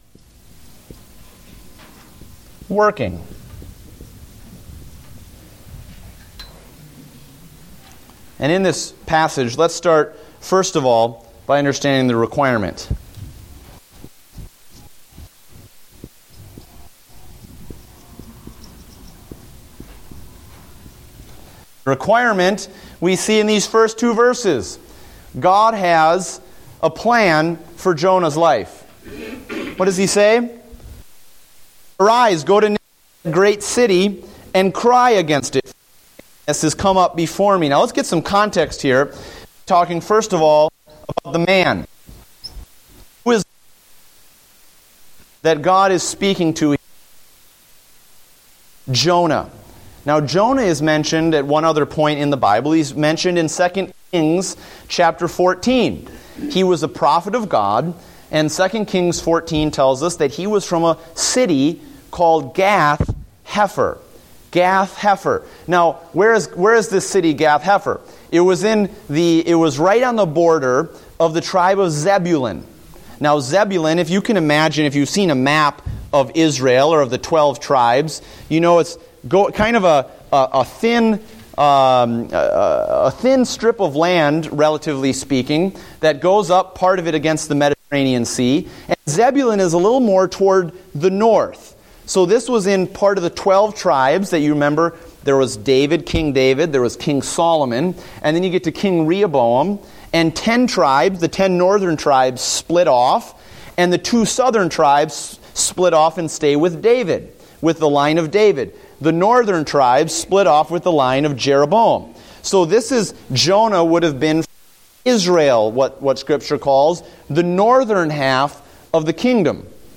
Date: November 9, 2014 (Adult Sunday School)